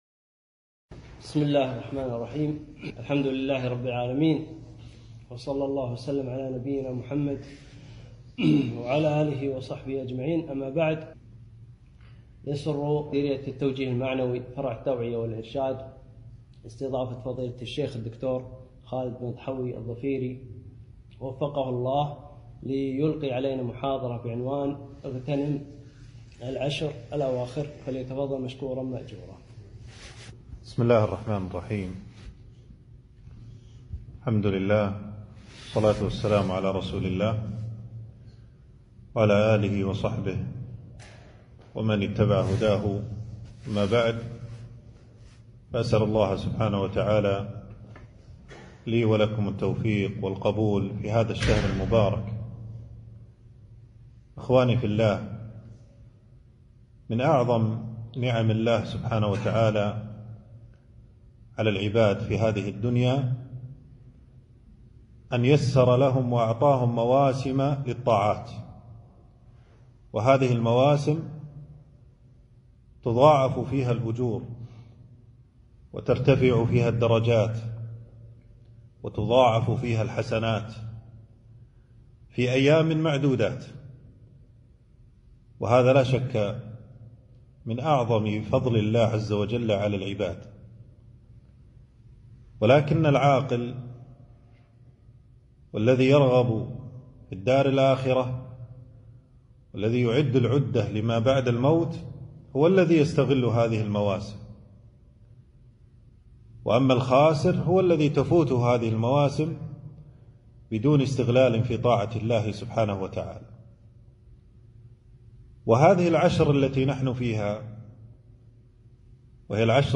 محاضرة - اغتنام العشر الأواخر من رمضان